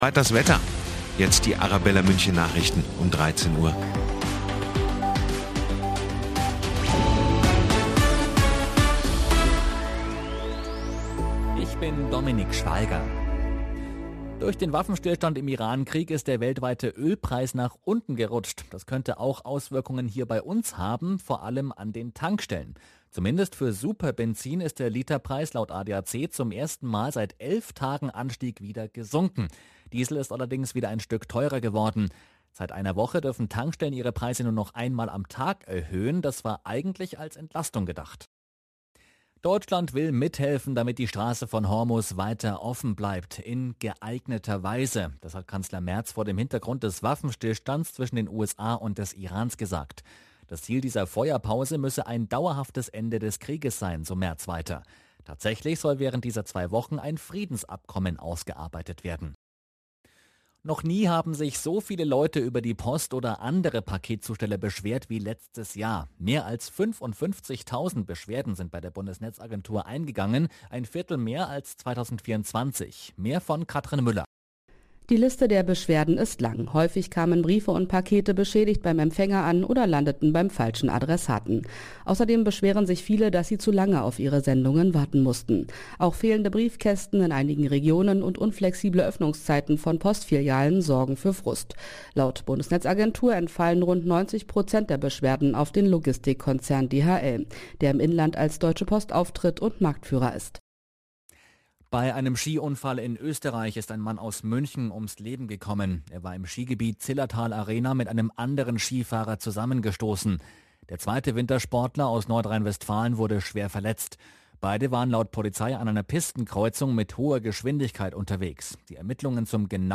Nachrichten , Nachrichten & Politik
Die aktuellen Nachrichten zum Nachhören